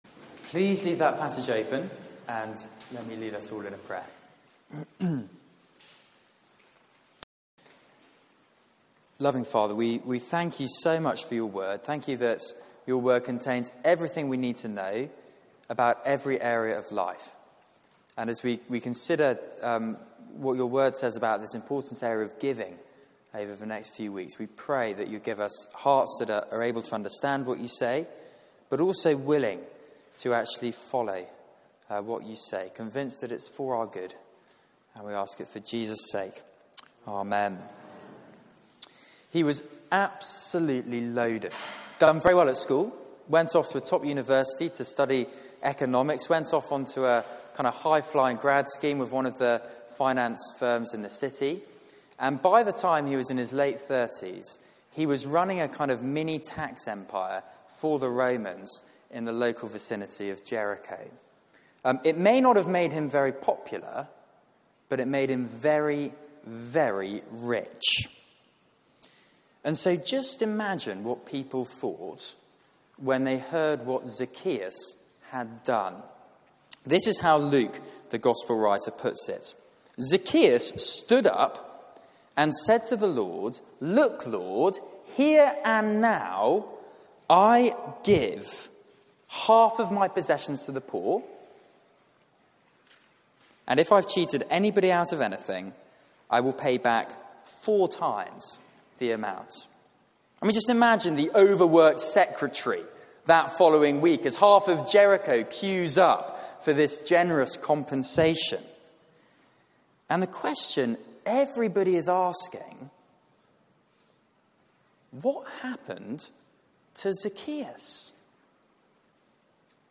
Media for 6:30pm Service on Sun 20th May 2018 18:30 Speaker
Theme: The grace of giving Sermon